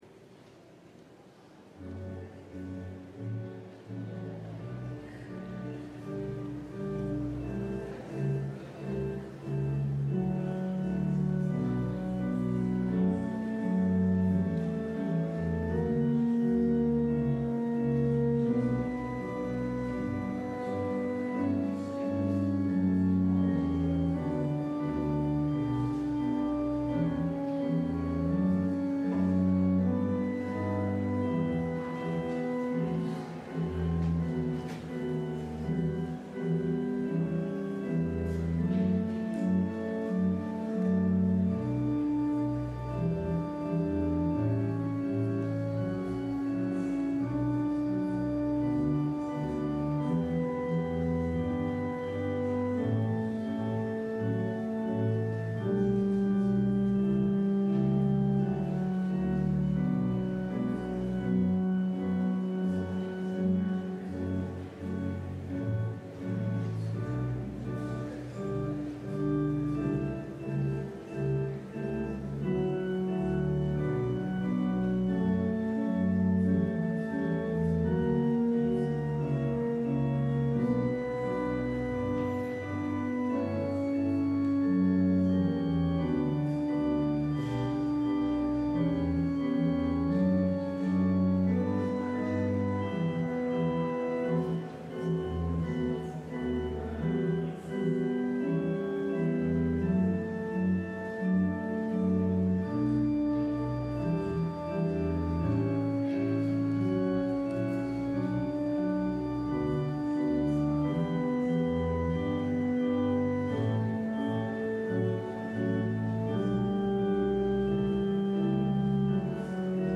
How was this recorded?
LIVE Morning Worship Service - Faith 101: Original Sin